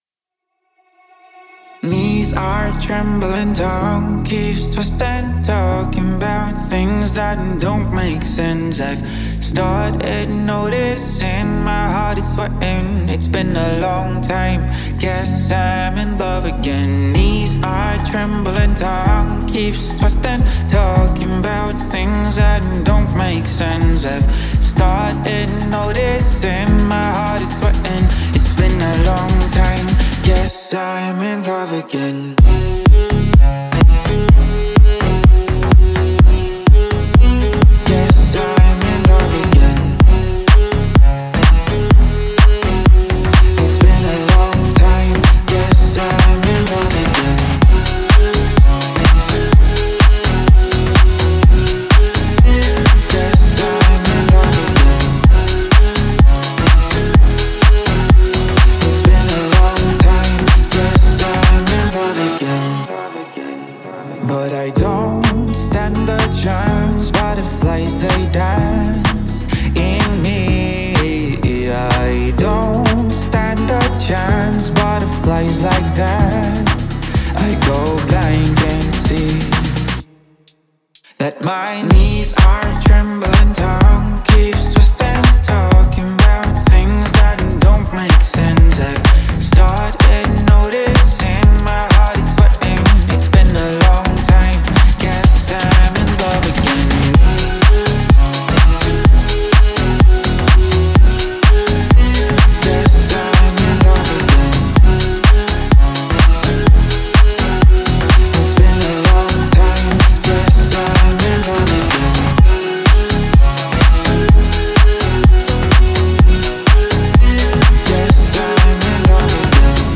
передает атмосферу легкости и танцевального настроения